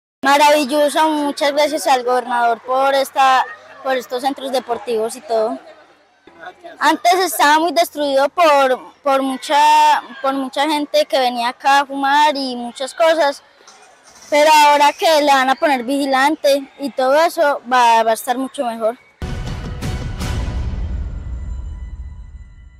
deportista beneficiado